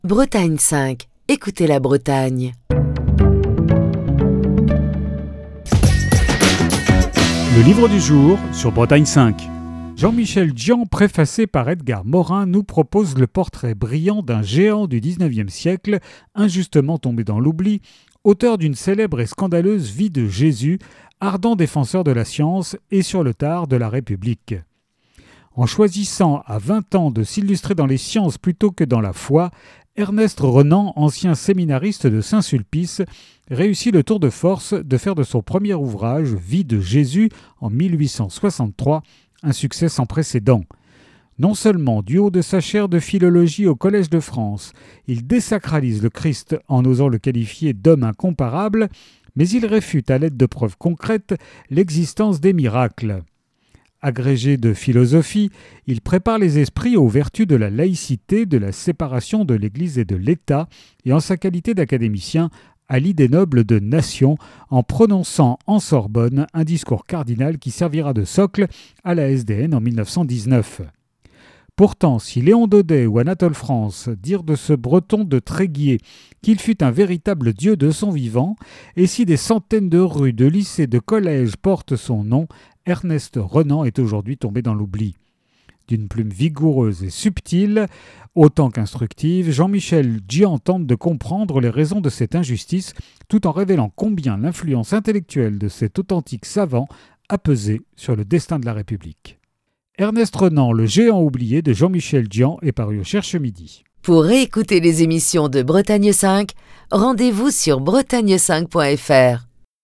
Chronique du 1er mai 2025.